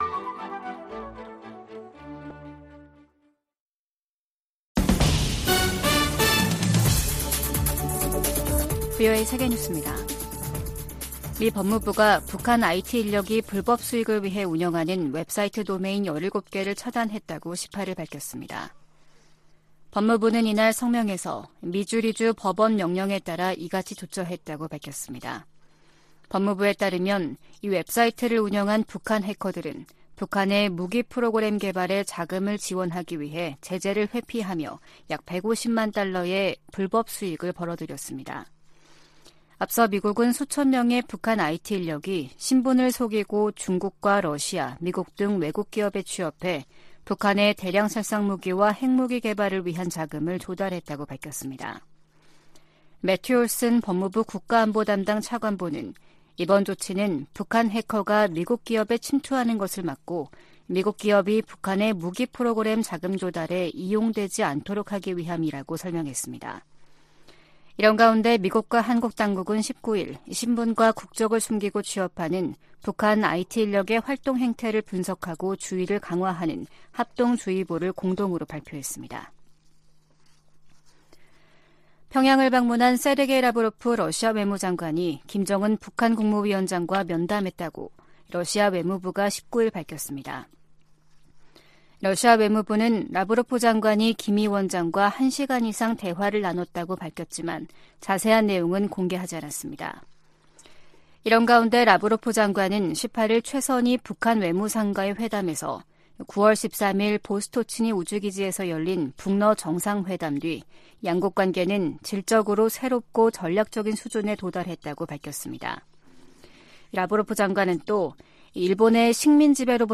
VOA 한국어 아침 뉴스 프로그램 '워싱턴 뉴스 광장' 2023년 10월 20일 방송입니다. 북한을 방문한 세르게이 라브로프 러시아 외무장관은 양국 관계가 질적으로 새롭고 전략적인 수준에 이르렀다고 말했습니다. 미 상원의원들은 북-러 군사 협력이 러시아의 우크라이나 침략 전쟁을 장기화하고, 북한의 탄도미사일 프로그램을 강화할 수 있다고 우려했습니다. 북한이 암호화폐 해킹을 통해 미사일 프로그램 진전 자금을 조달하고 있다고 백악관 고위 관리가 밝혔습니다.